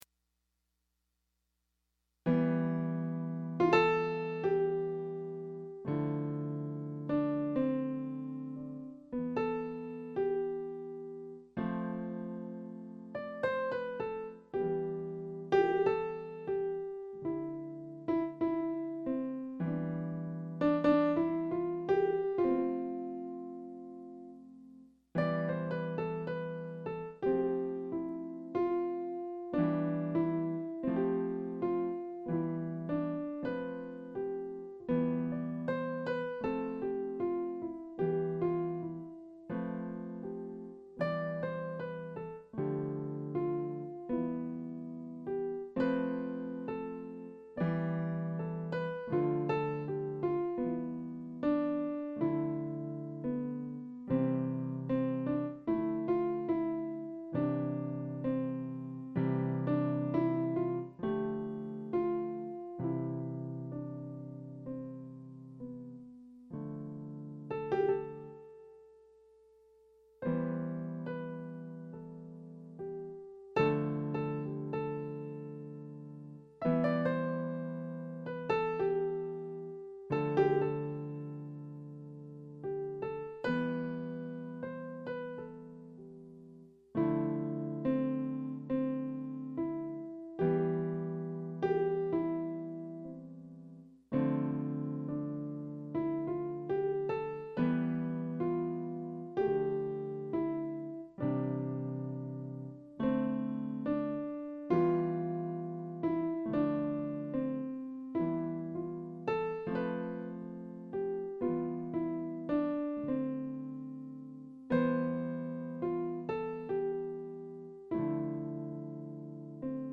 Improvisational Piano without a net I (raw unedited)
I gave myself a challenge this evening. Turn on my Synth, fire up Audioboo and just play.